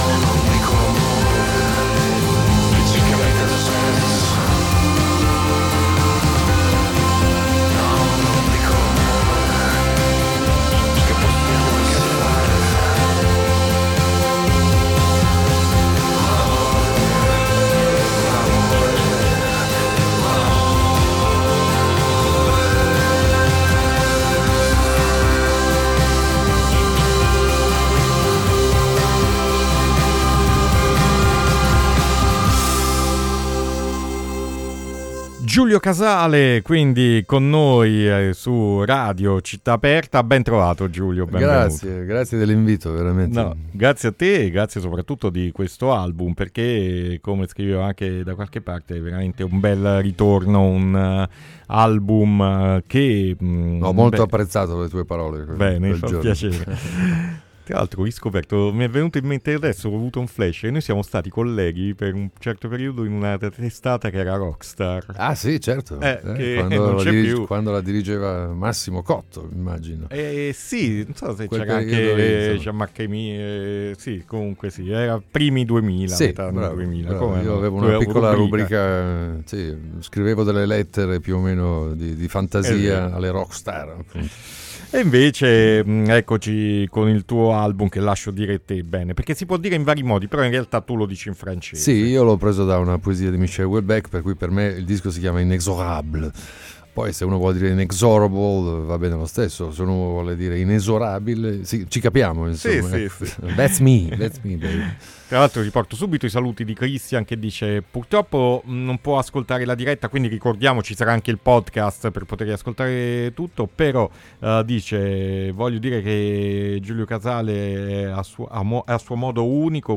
Intervista Giulio Casale 6-5-2019 | Radio Città Aperta